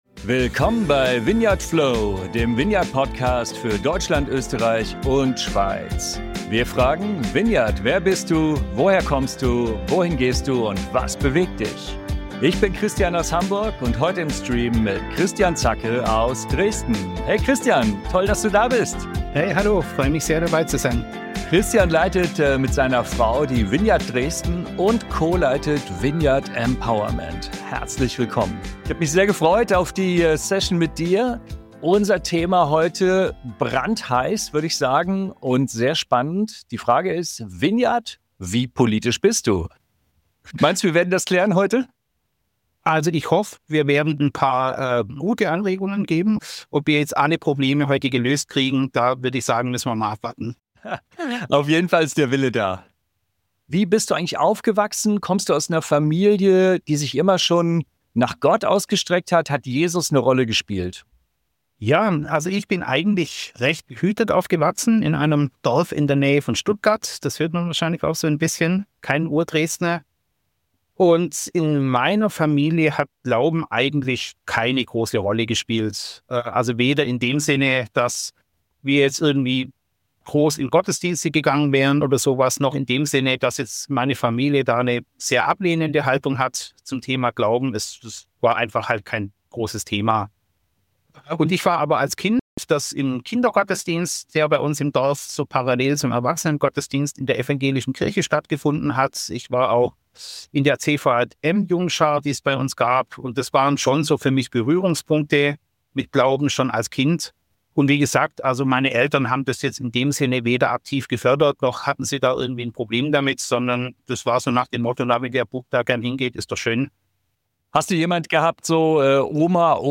Bitte entschuldigt die suboptimale Tonqualität in dieser Folge! In dieser Folge geht es um die Spannung zwischen Glauben und Politik.